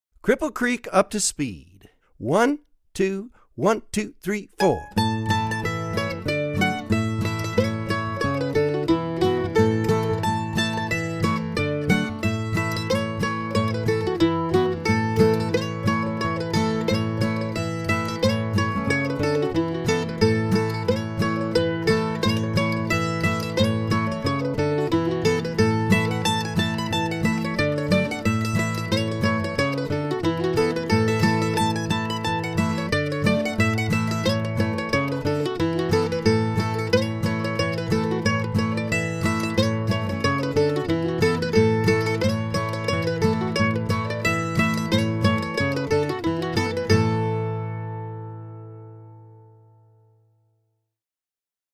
DIGITAL SHEET MUSIC - MANDOLIN SOLO
Online Audio (both slow and regular speed)